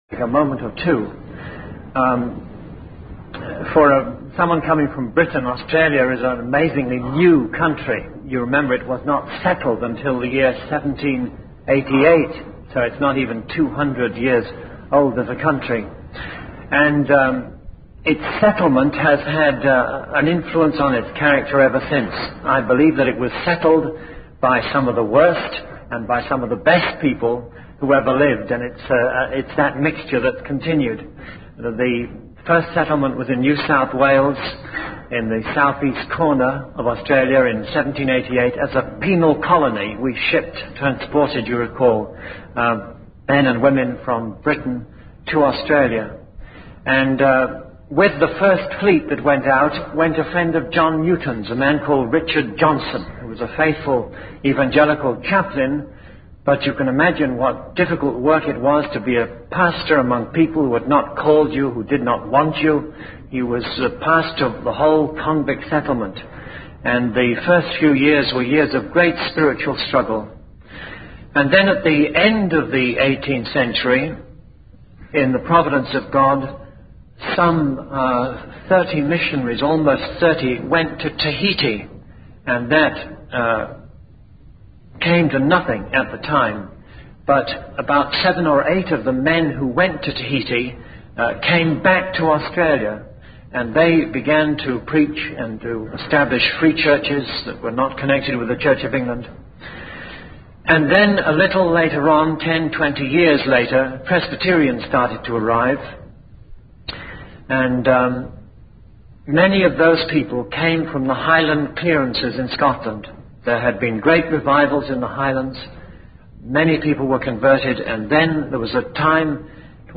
In this sermon, the preacher emphasizes the fleeting nature of worldly possessions and the futility of seeking happiness in them.